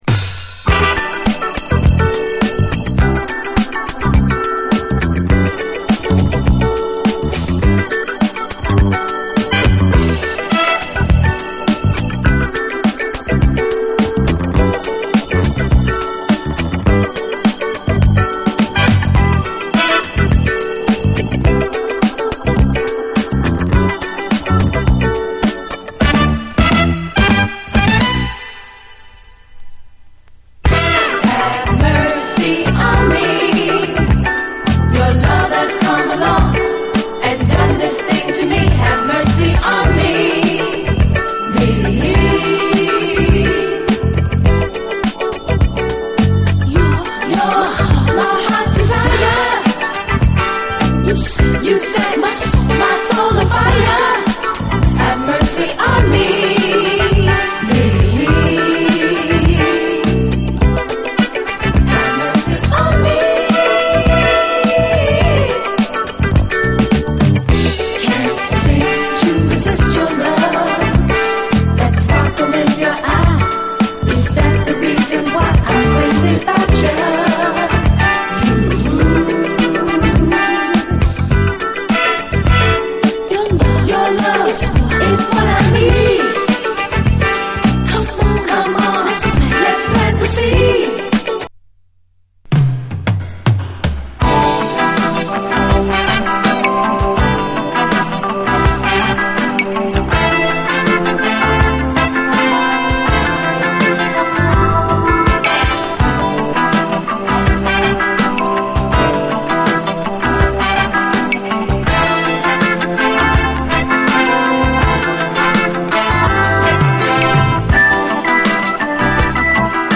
SOUL, JAZZ FUNK / SOUL JAZZ, 70's～ SOUL, JAZZ
キリリとレアグルーヴ臭漂うグレイト・トラック！後半にはオールドスクール・ラッピンも飛び出します！